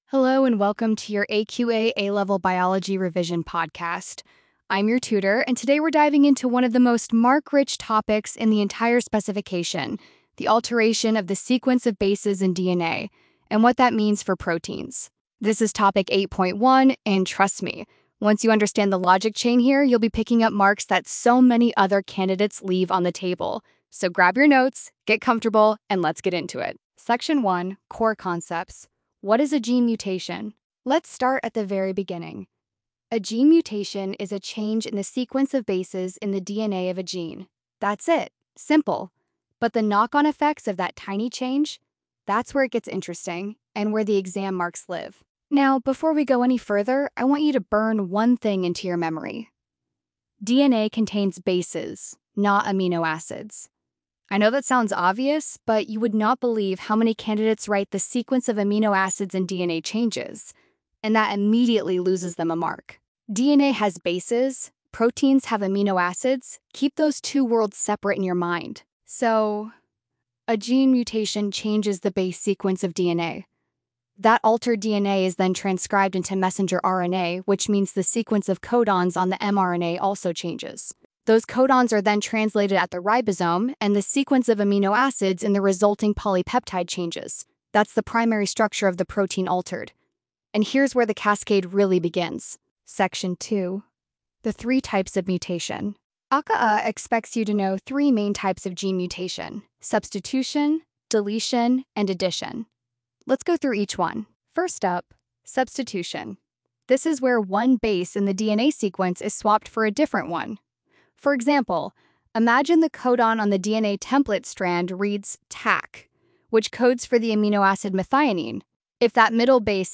Revision podcast for AQA A-Level Biology Topic 8.1.